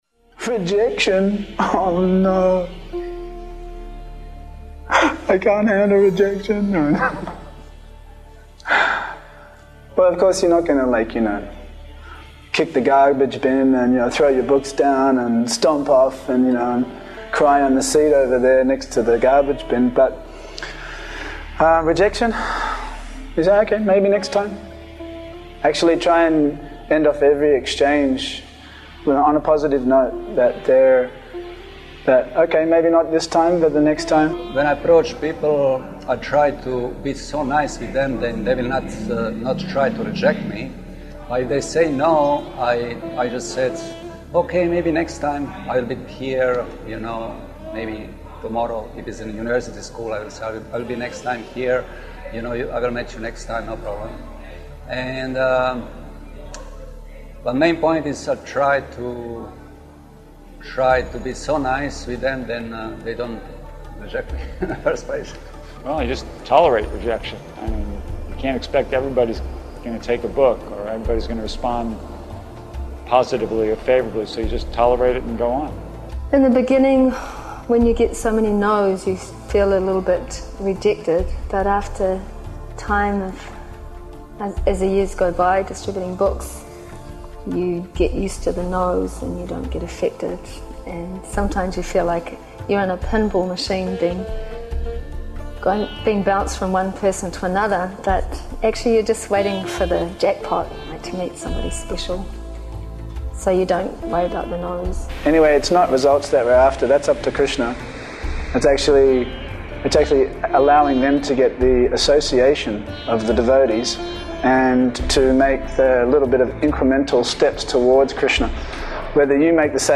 Lecture
Audio lecture